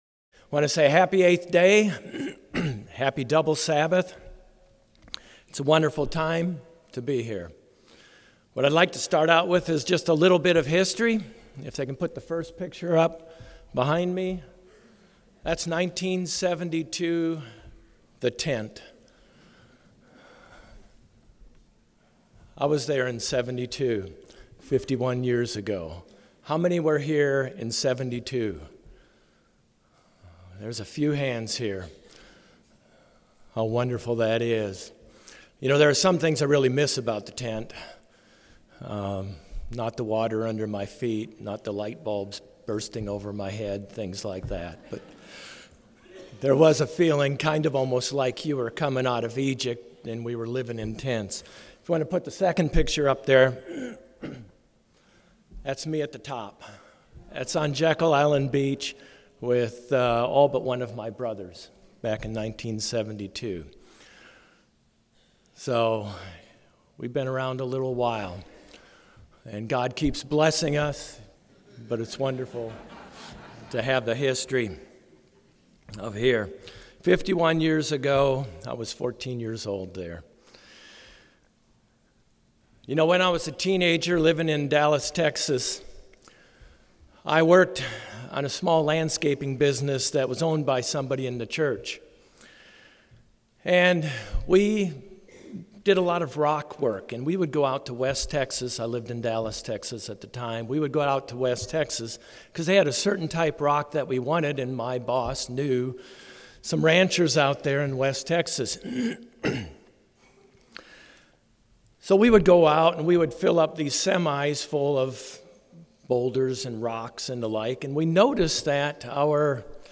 This sermon was given at the Jekyll Island, Georgia 2023 Feast site.